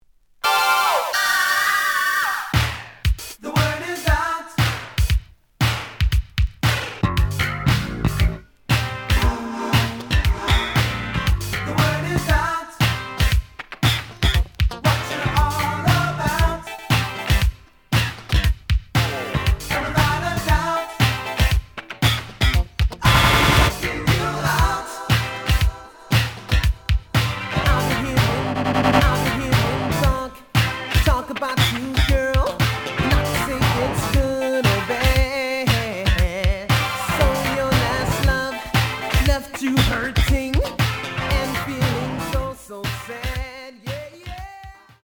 試聴は実際のレコードから録音しています。
The audio sample is recorded from the actual item.
●Genre: Funk, 80's / 90's Funk